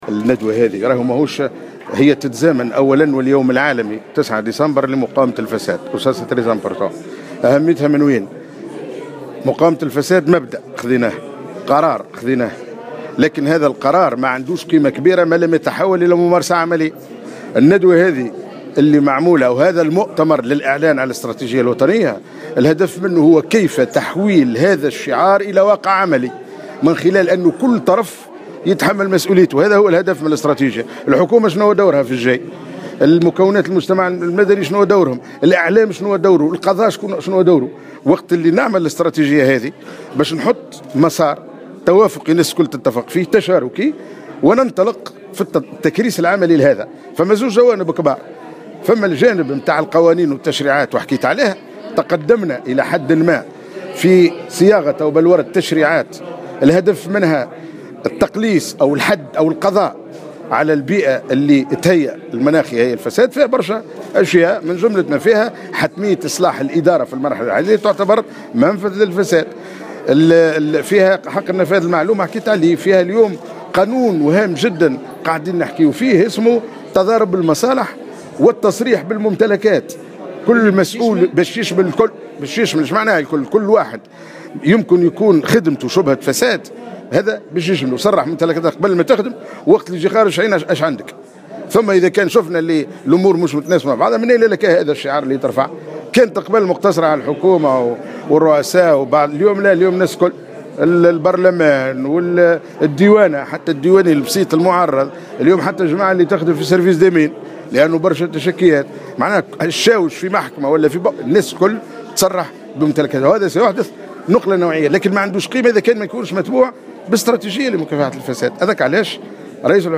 أكد وزير الوظيفة العمومية عبيد البريكي في تصريح لمراسل الجوهرة "اف ام" اليوم الخميس 8 ديسمبر 2016 على هامش انعقاد ندوة وطنية للإعلان عن الإستراتيجية الوطنية لمقاومة الفساد أن شعار مقاومة الفساد لن يكون له قيمة كبيرة ما لم يتحول إلى ممارسة عملية من خلال أن يتحمل كل طرف مسؤوليته.